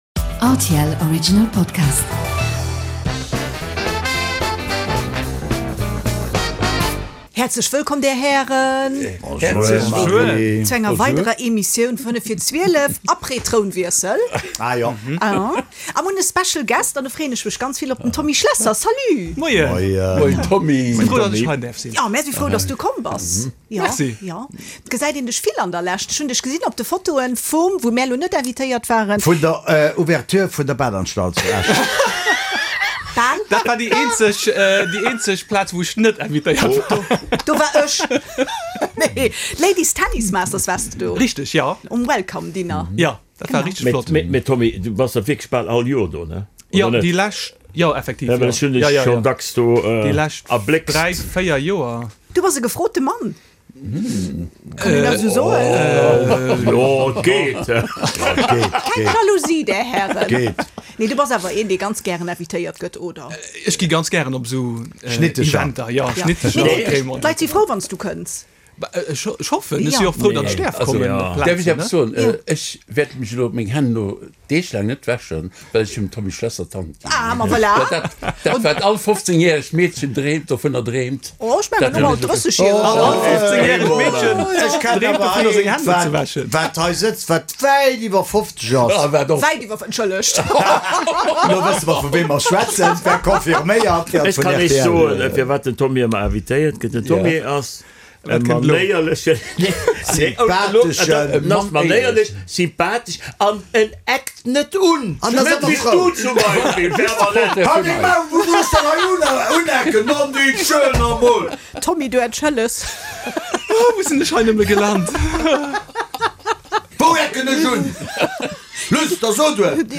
Eng Table-Ronde mat Prominenz a Perséinlechkeeten déi mat vill Witz a bëssegem Humor d'Aktualitéit kommentéieren